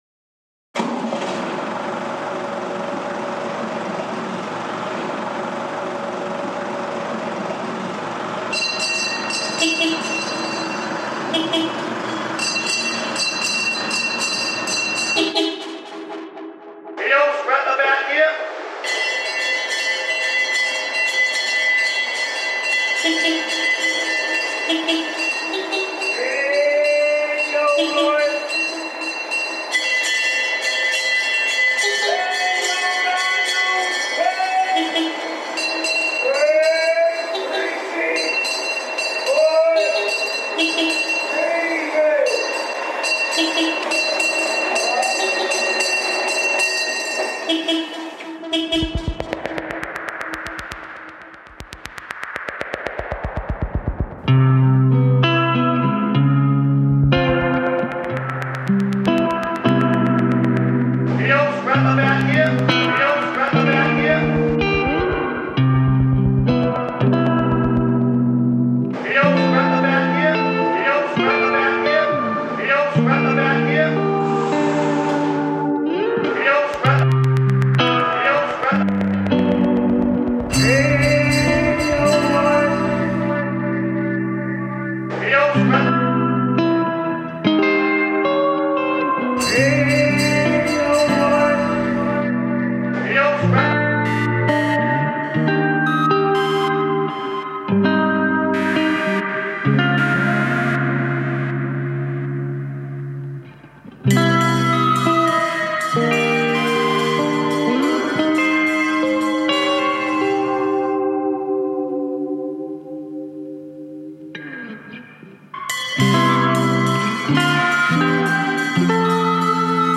Rag 'n' bone man call reimagined